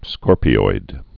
(skôrpē-oid)